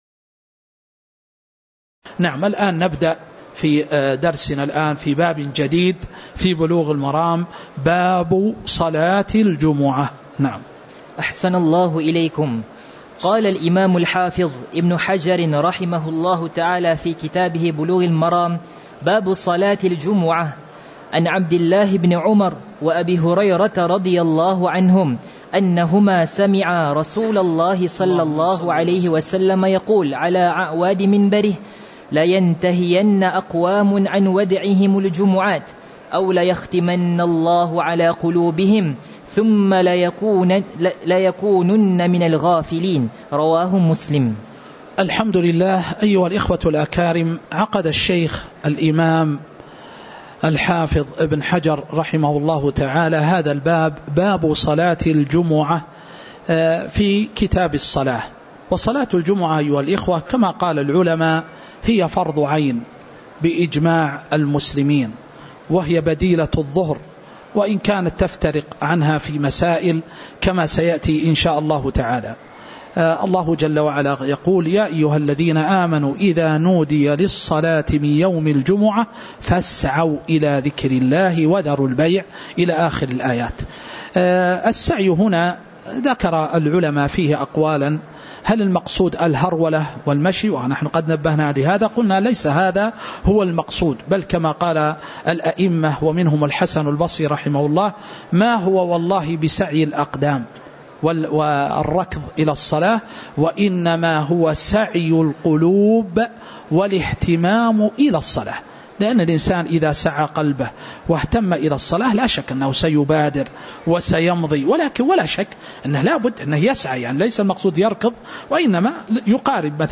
تاريخ النشر ٩ رجب ١٤٤٥ هـ المكان: المسجد النبوي الشيخ